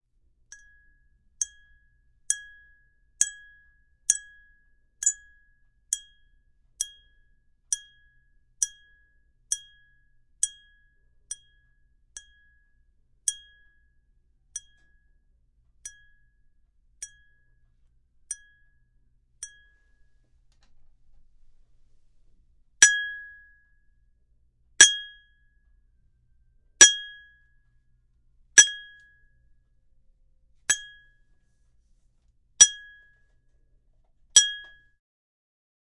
水冷却器运行关闭
描述：这是一个水冷却器在冷藏水时发出的噪音。我在接近尾声时抓住了它。
Tag: 水冷却器 运行 关闭